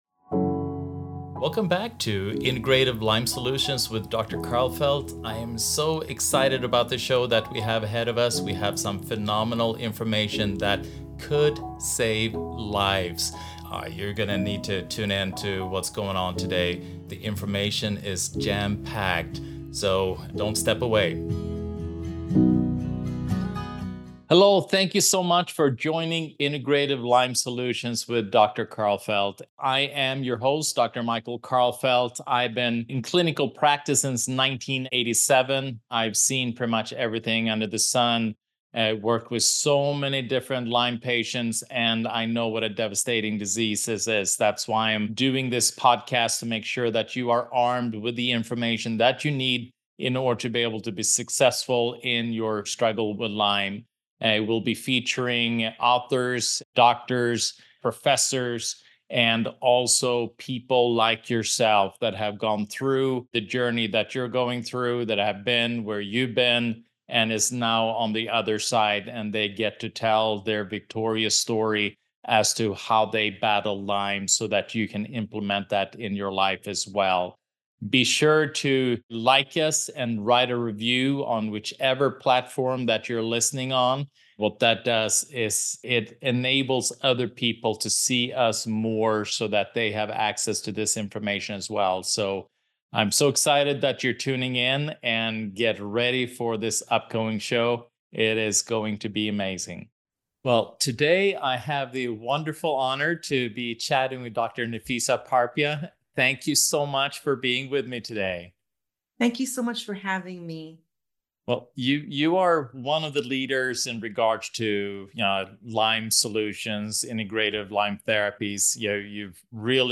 Synergy of Science and Healing: A Conversation